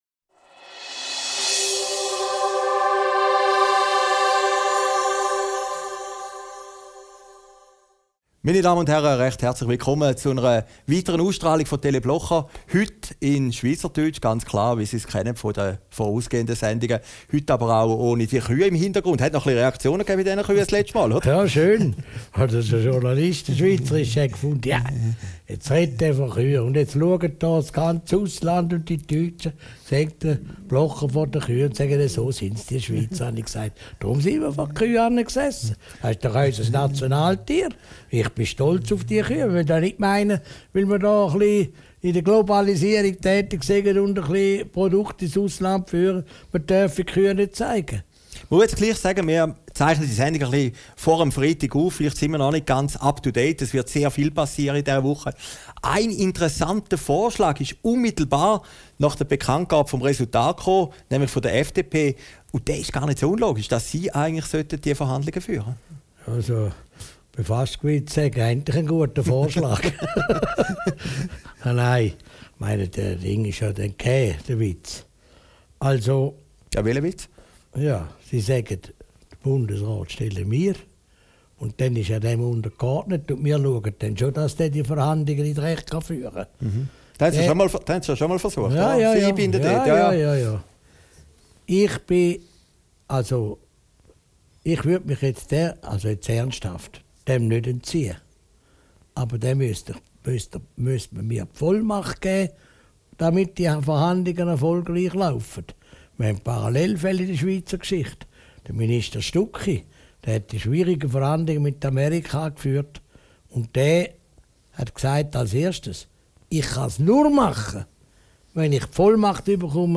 Video downloaden MP3 downloaden Christoph Blocher über das Angebot der FDP und die Geschäfte von Johannes Schneider-Ammann Aufgezeichnet im Berner Oberland, 09.